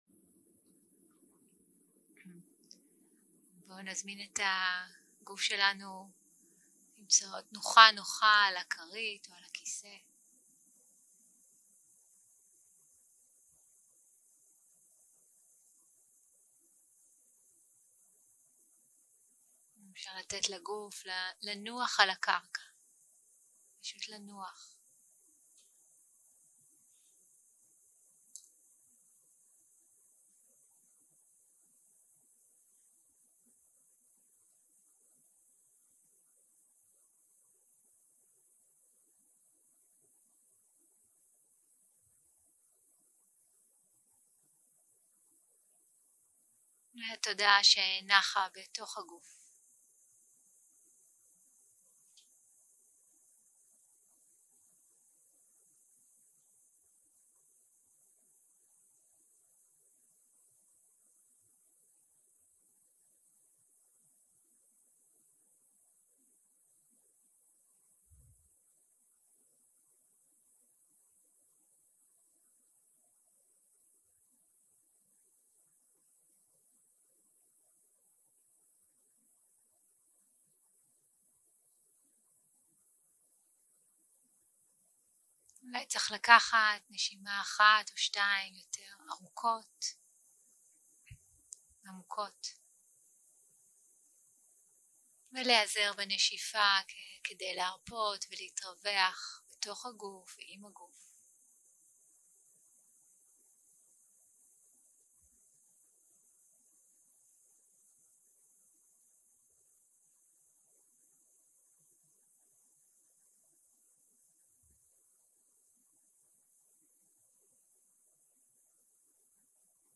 יום 1 - הקלטה 2 - ערב - שיחת דהרמה - הדרשה על חציית השיטפון
סוג ההקלטה: שיחות דהרמה